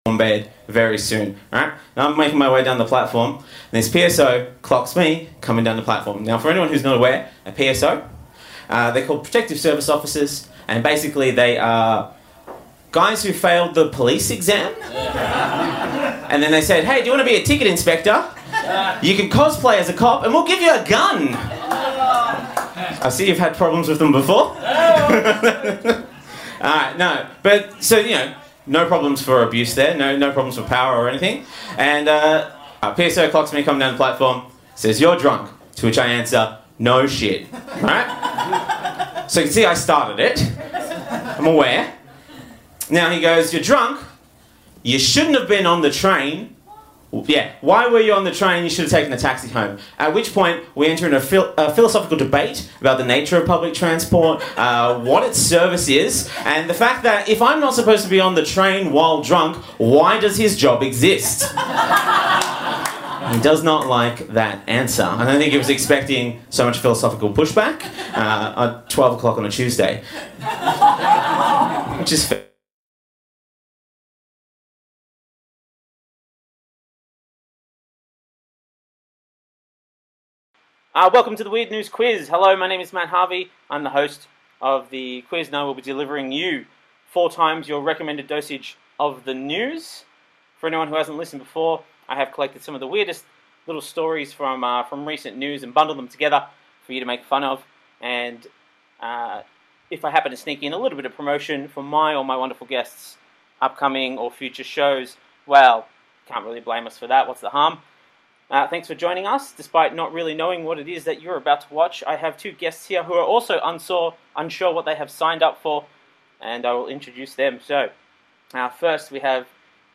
Watch our guests battle it out in a high energy news quiz. What’s the weirdest news you’ve read this week?